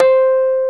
RHODES2H C5.wav